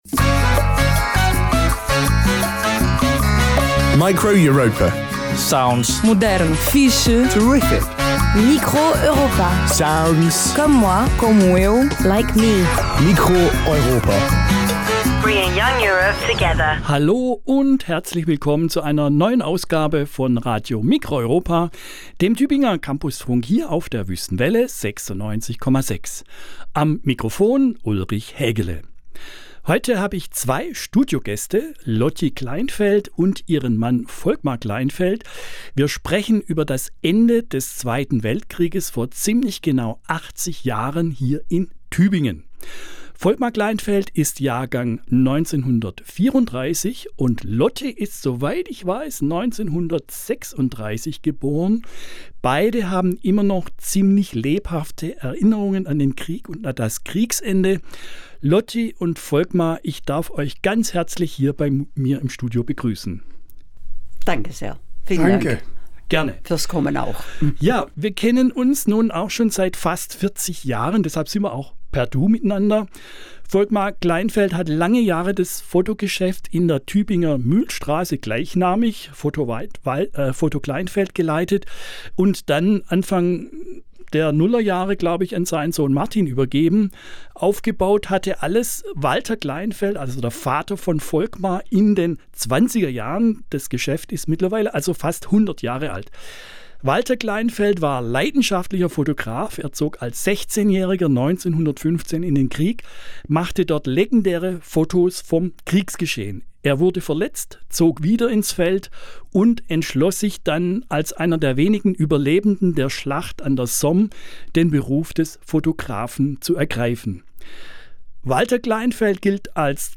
Studiogespräch
Form: Live-Aufzeichnung, geschnitten